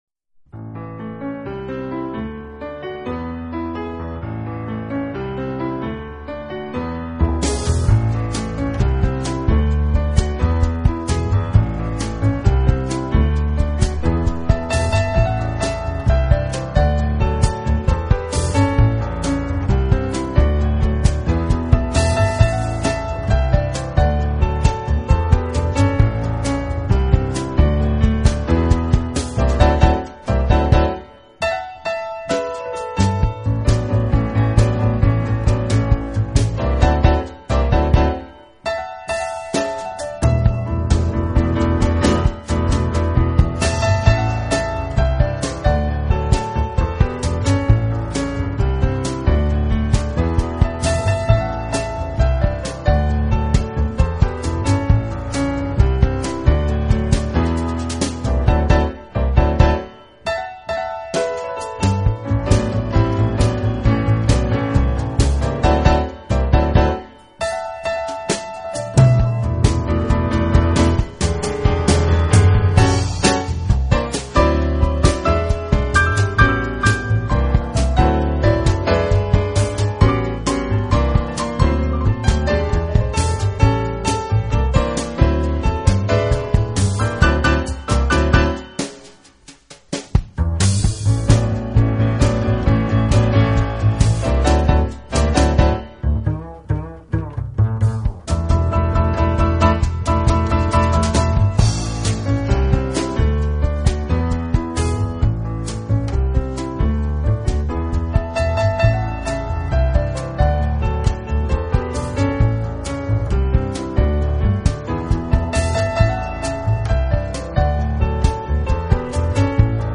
音乐类型：Jazz，Piano